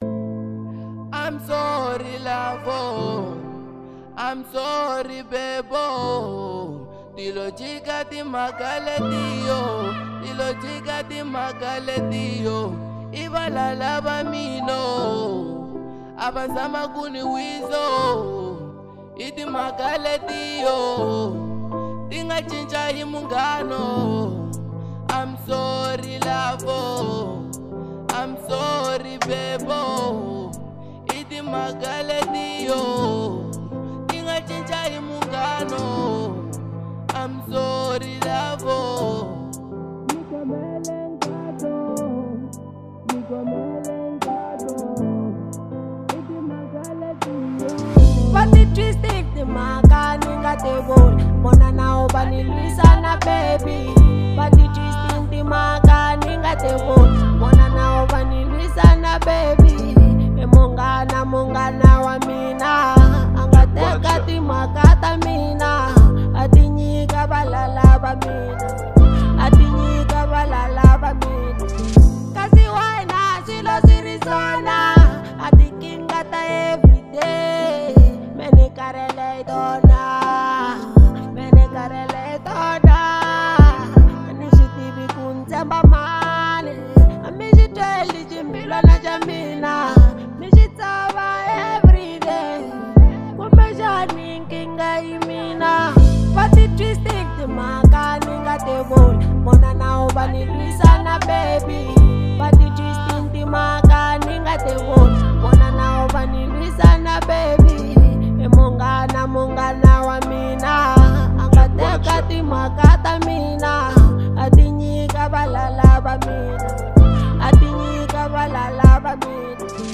02:50 Genre : RnB Size